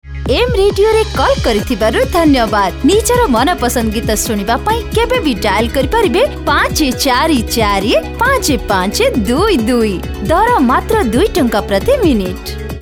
Oriya Voice Over Sample
Oriya Voice Over Female Artist -2